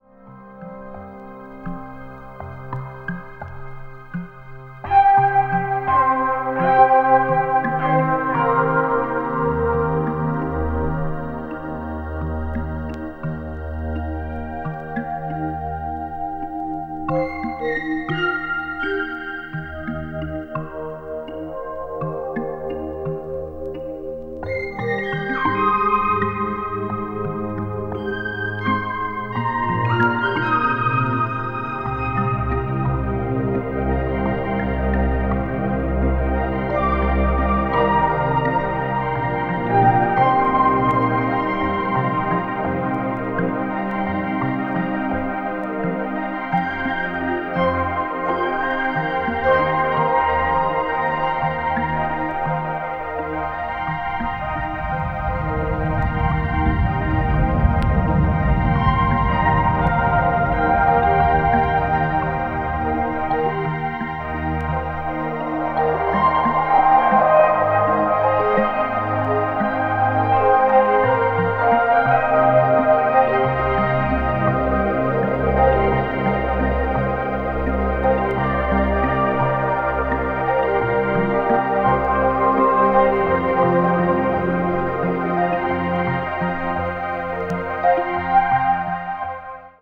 ピアノやシンセサイザーが静かに広がって行くメディテーティヴな世界